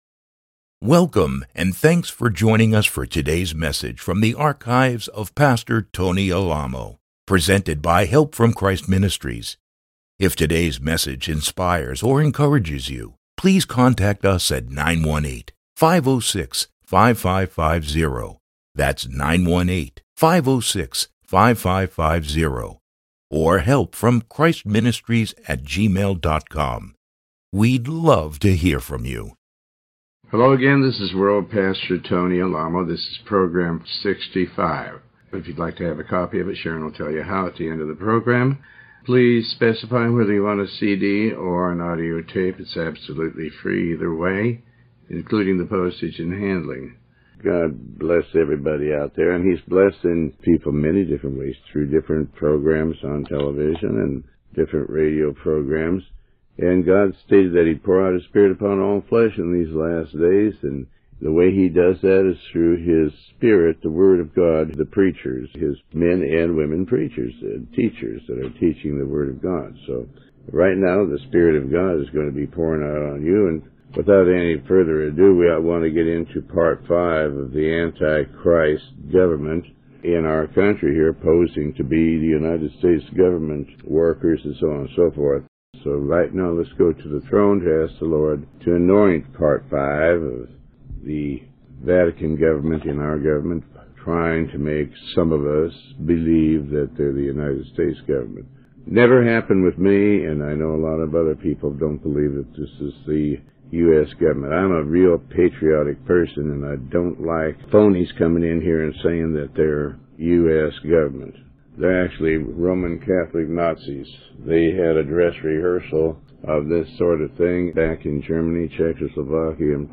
Sermon 65